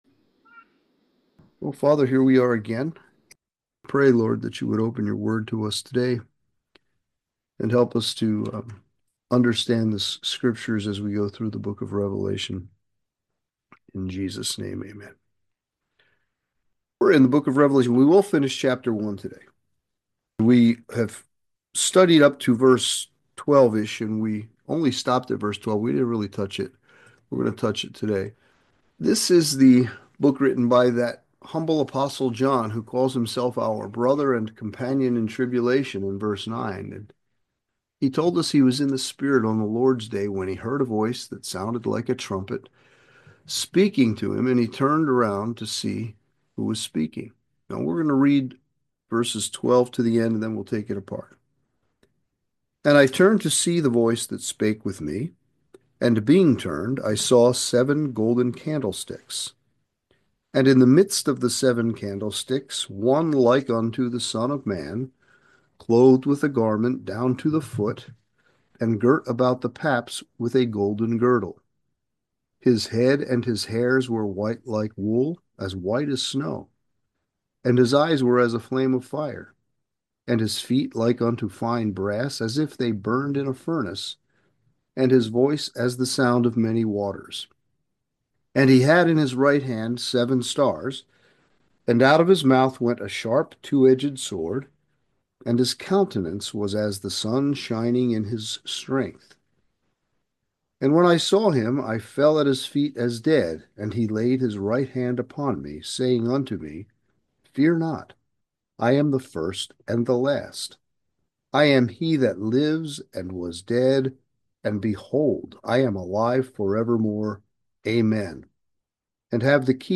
Sermons | Calvary Chapel on the King's Highway